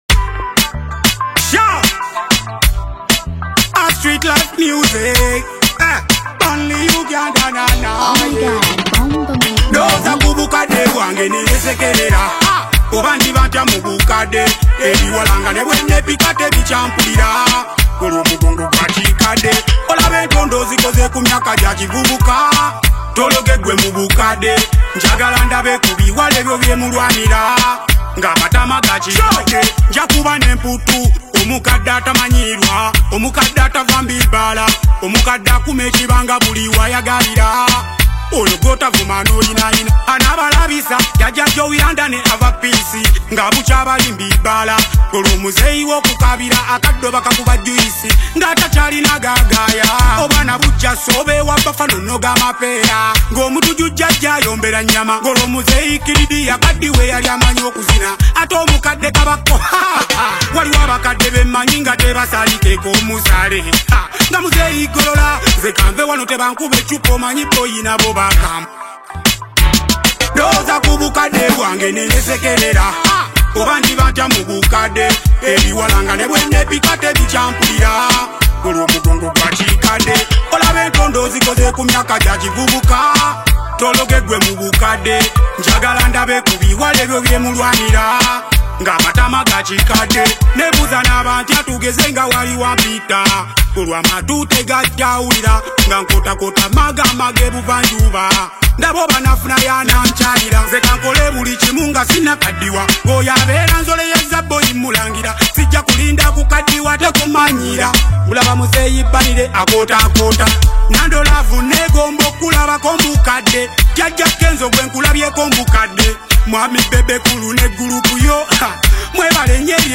Genre: Dance Hall